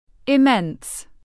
Immense /ɪˈmens/